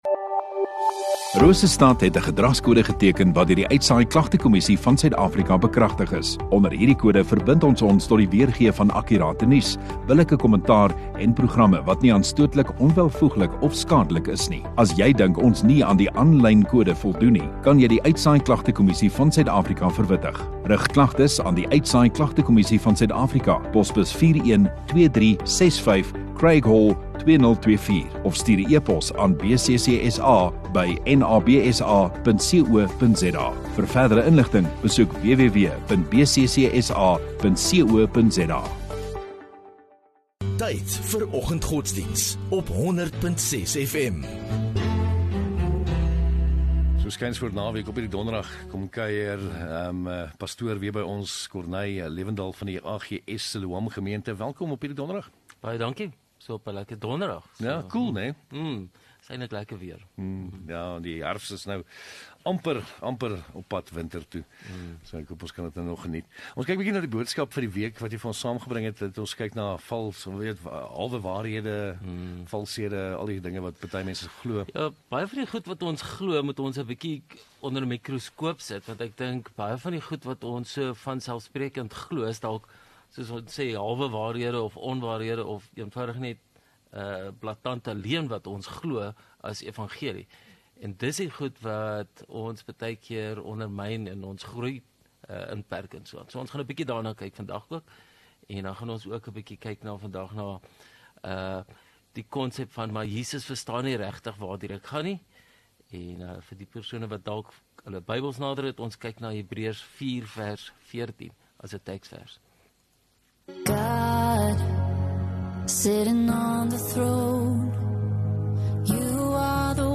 11 Apr Donderdag Oggenddiens